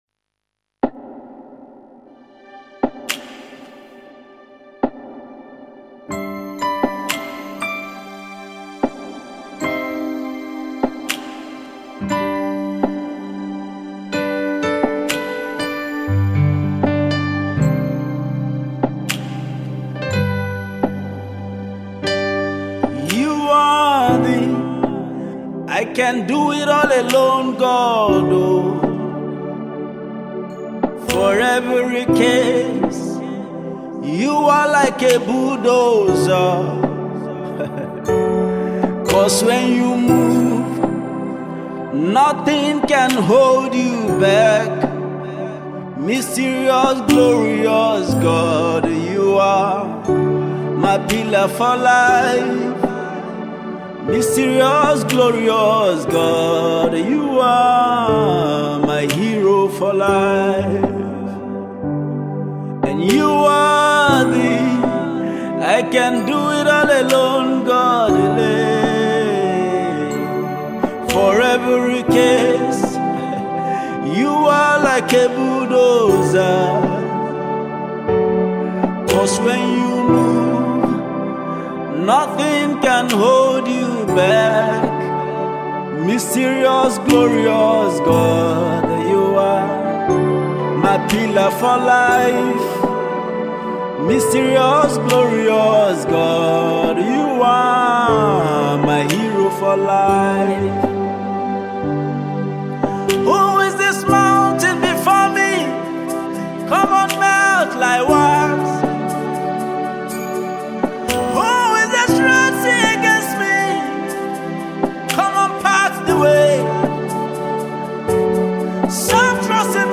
Gospel Music Minister